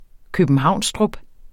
Udtale [ købənˈhɑwˀnsdʁub ]